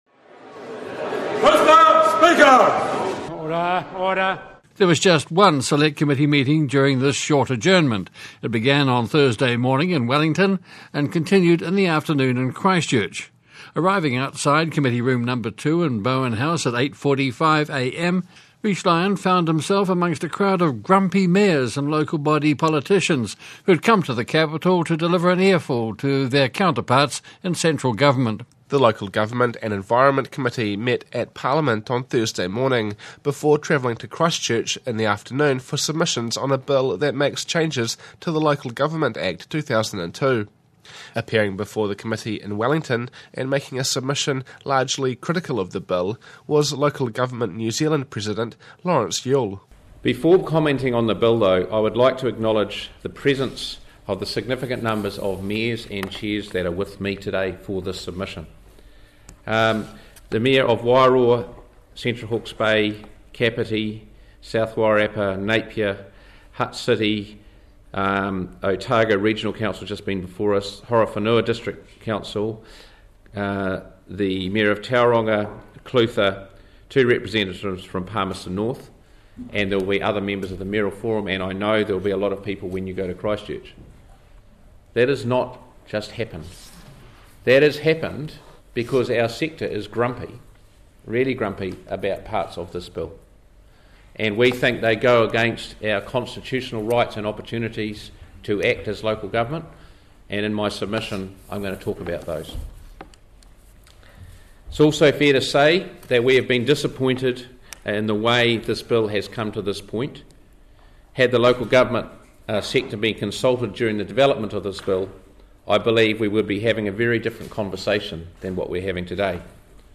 this week featuring additional audio from public submissions on the Local Government Act 2002 Amendment Bill.